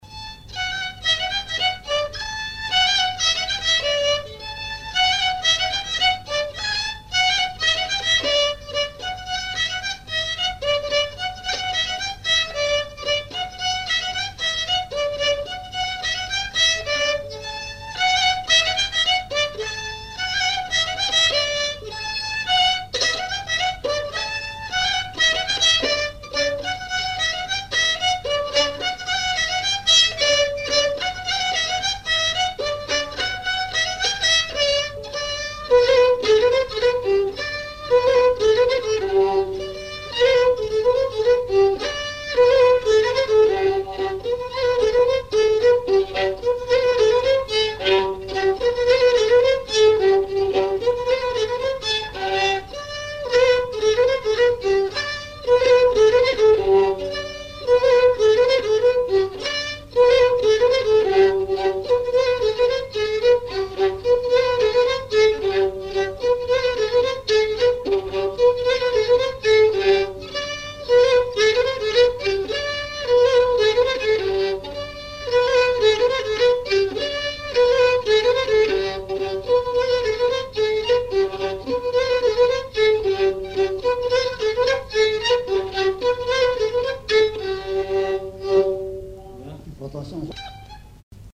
Mémoires et Patrimoines vivants - RaddO est une base de données d'archives iconographiques et sonores.
danse : polka piquée
enregistrements du Répertoire du violoneux
Pièce musicale inédite